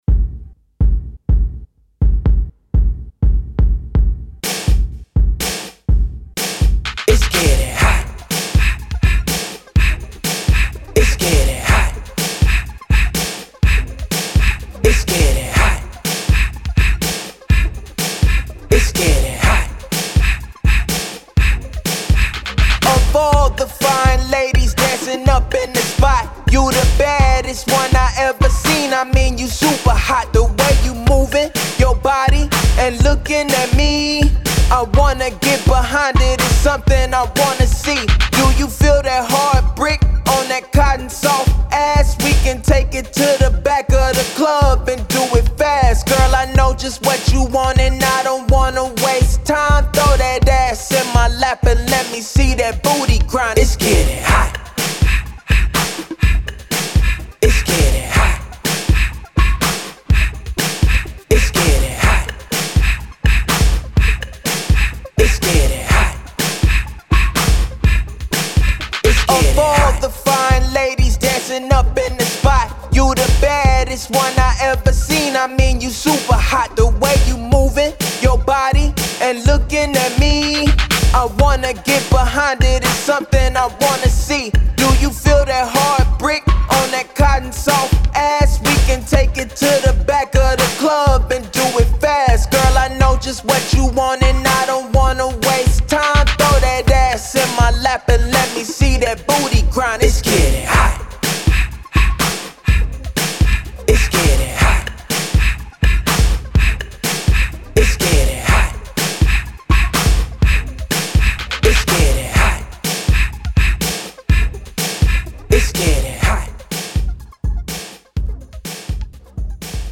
Hip Hop, 80s
Bb Major